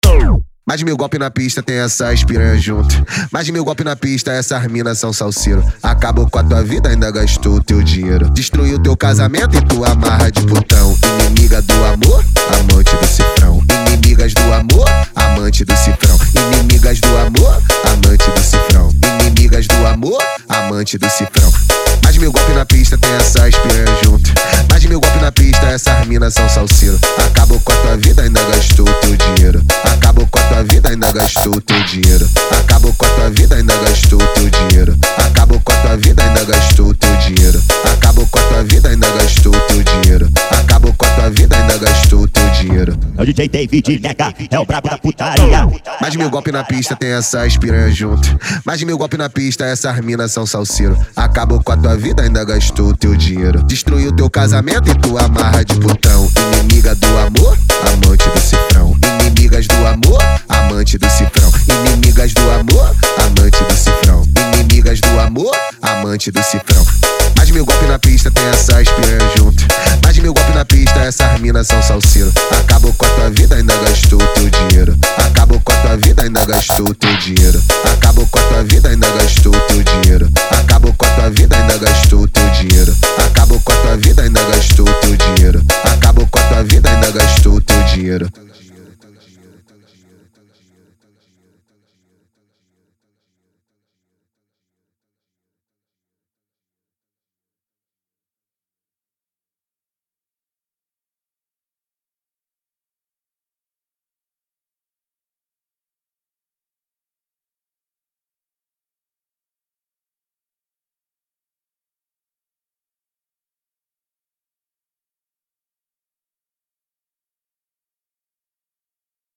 Tecnofunk